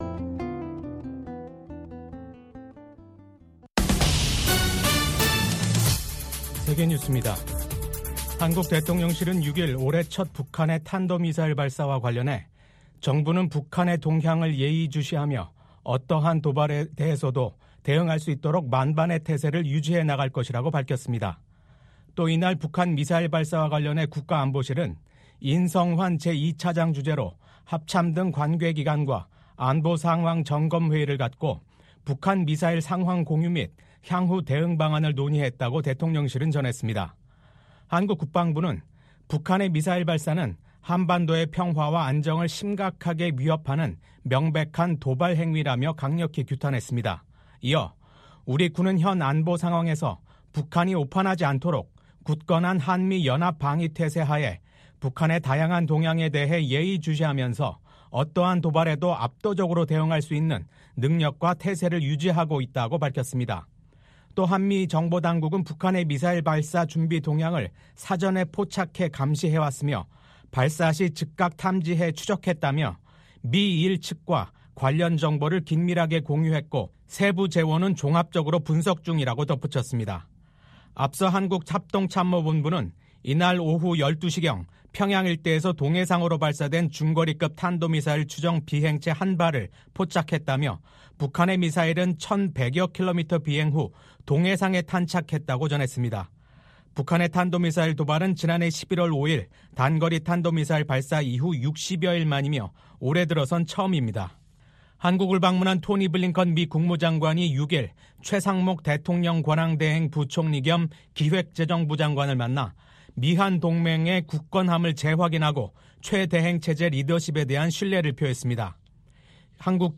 VOA 한국어 아침 뉴스 프로그램 '워싱턴 뉴스 광장'입니다. 한국의 비상계엄 사태 이후 토니 블링컨 미국 국무장관이 한국을 처음으로 방문했습니다. 한국을 방문한 블링컨 장관과 조태열 외교장관이 서울 외교부 청사에서 미한 외교장관 회담을 연 오늘 북한은 극초음속 미사일로 추정되는 탄도미사일을 동해상에 발사했습니다.